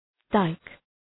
Shkrimi fonetik {daık}